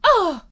peach_ooof2.ogg